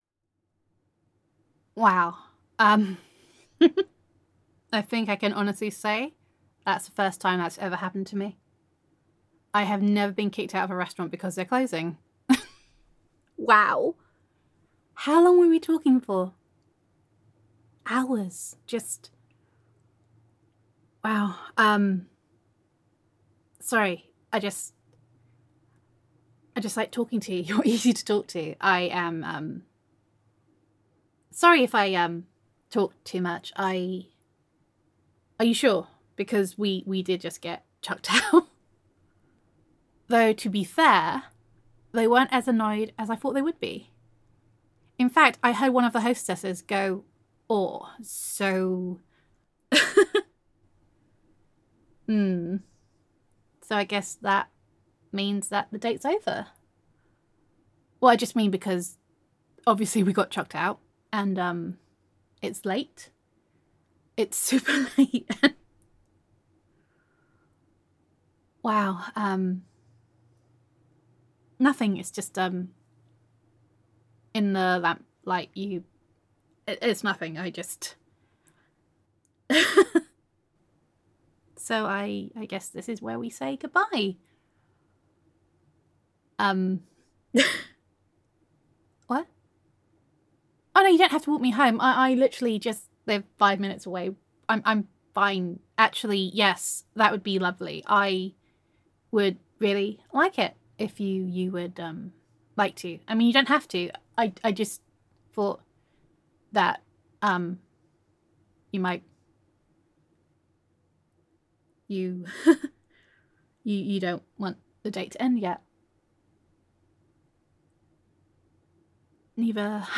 [F4A] Walk Me Home
[I Giggle When I Am Nervous]